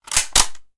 CitadelStationBot 14427a2b8c Ballistic Weapon/Shotgun Reload Sounds 2017-05-24 05:52:37 -05:00 16 KiB Raw History
autoguninsert.ogg